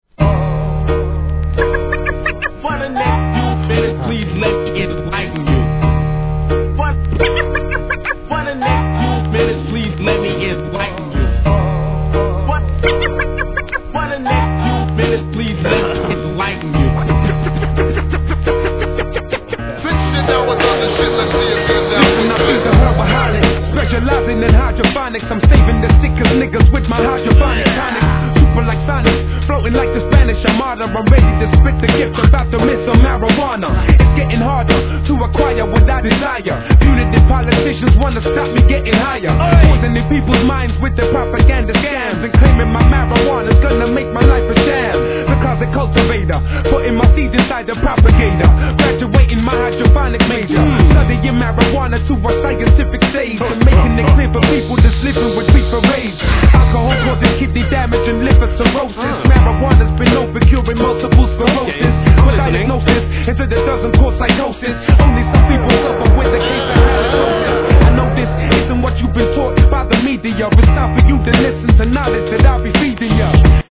HIP HOP/R&B
タイトル通りのスモーキーな逸品!!